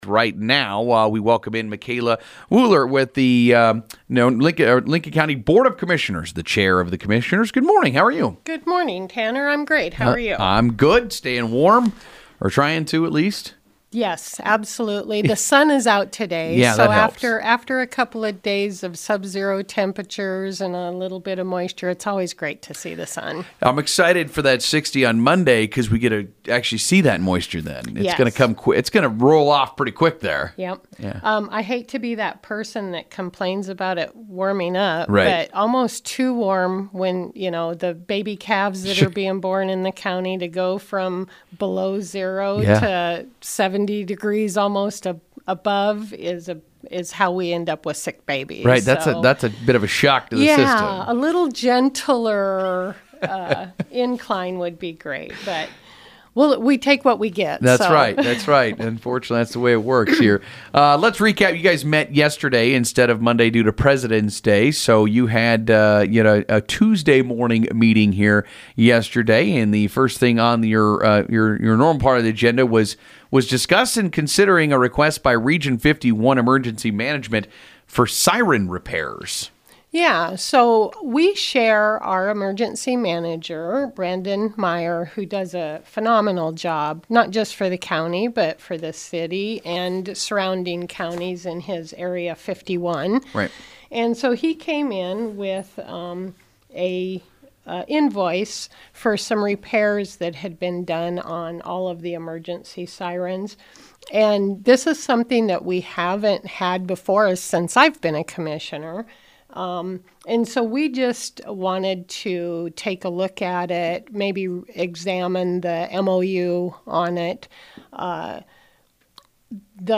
Board Chair Micaela Wuehler recapped the meeting on Huskeradio’s Mugs in the Morning on Wednesday morning. Listen to the full interview below.